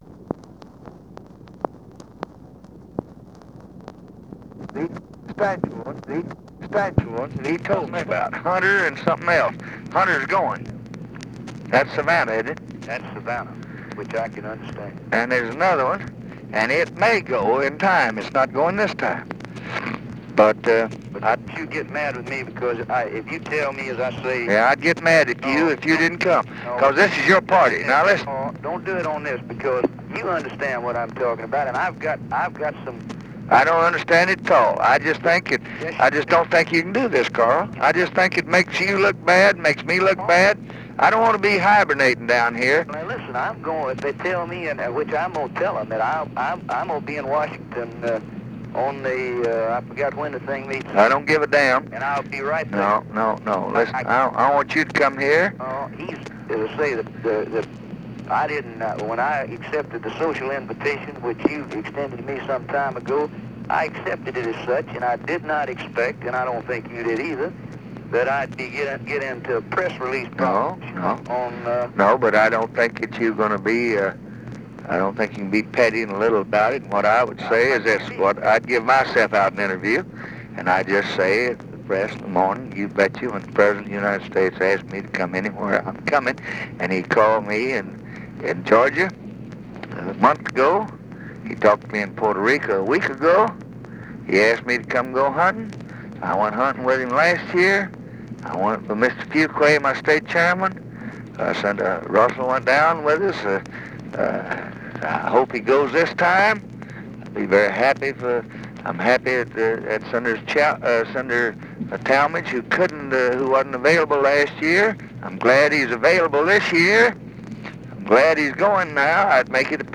Conversation with CARL SANDERS, November 12, 1964
Secret White House Tapes